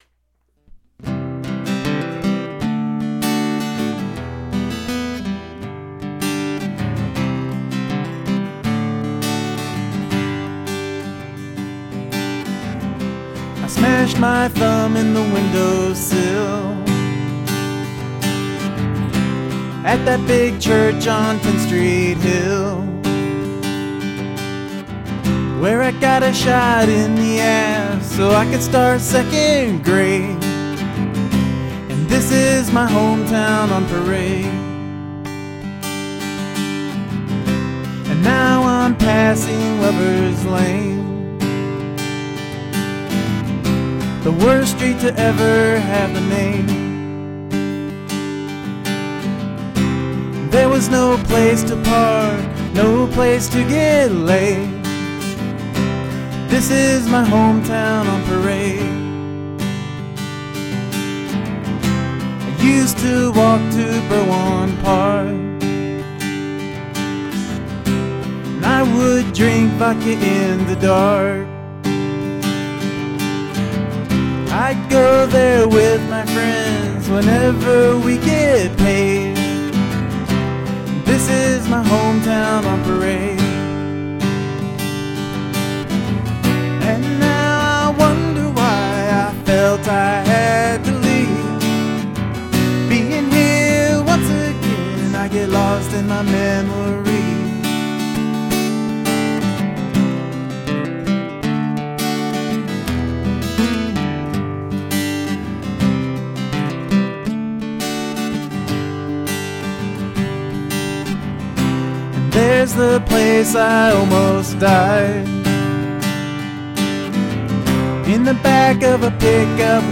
Outstanding acoustic playing. Great picking and singing!
Sung with a lot of feeling, Nice job